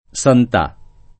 santà [ S ant #+ ]